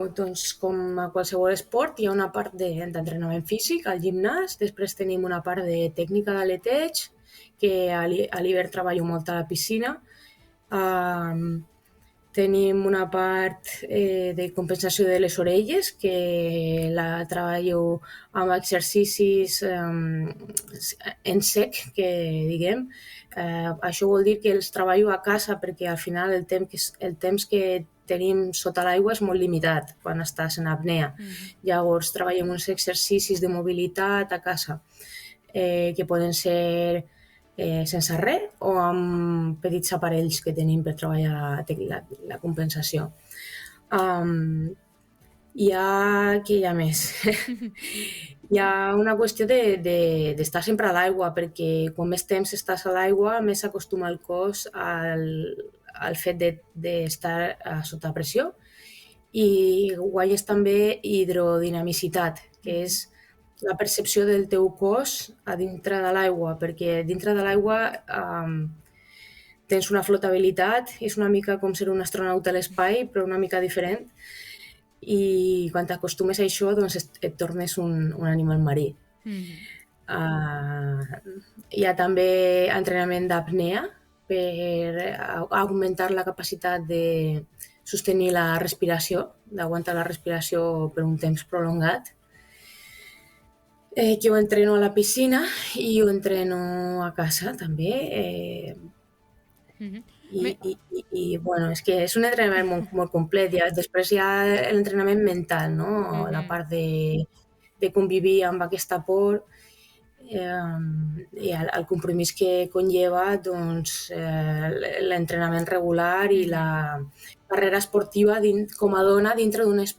En una entrevista concedida al programa El Supermatí